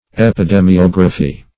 Search Result for " epidemiography" : The Collaborative International Dictionary of English v.0.48: Epidemiography \Ep`i*de`mi*og"ra*phy\, n. [Epidemy + -graphy.]